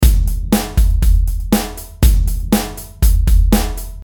se però ci accorgiamo che il charleston è ancora un po’troppo in evidenza, possiamo utilizzare l’Editor Logico per ridurne la presenza, modificandone la velocity globale